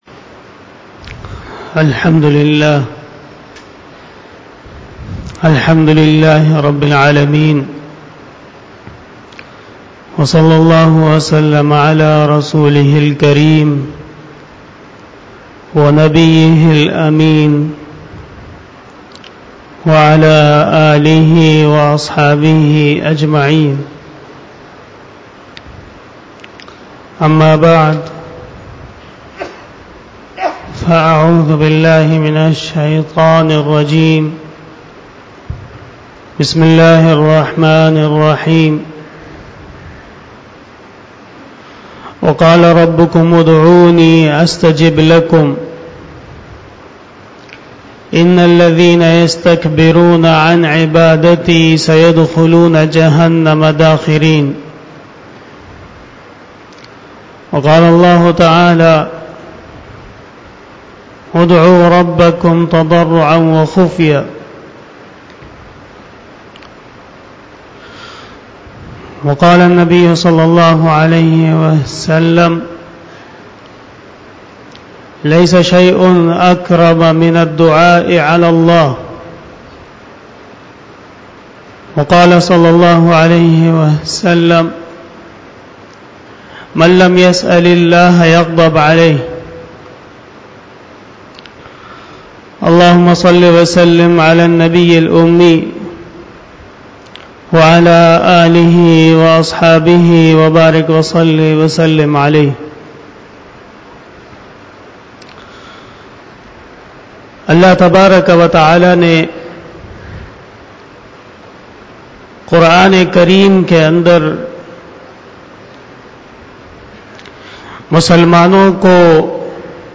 بیان جمعۃ المبارک
Khitab-e-Jummah